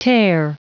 Prononciation du mot tare en anglais (fichier audio)
Prononciation du mot : tare